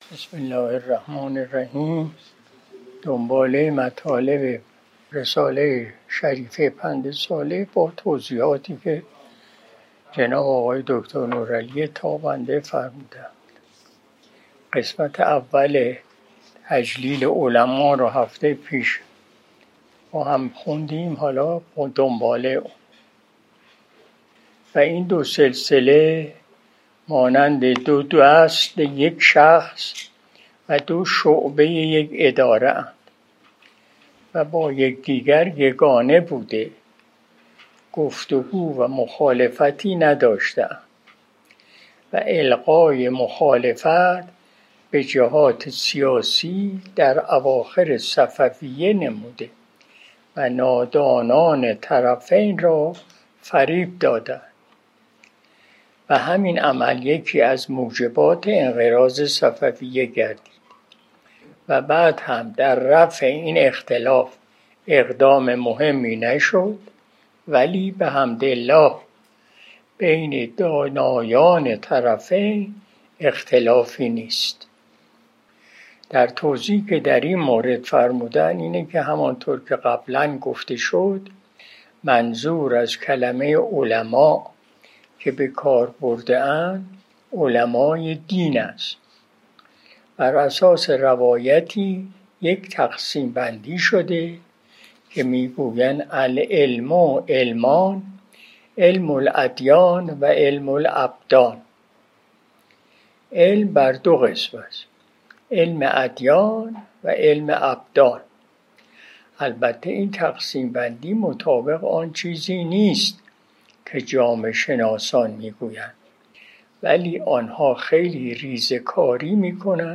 قرائت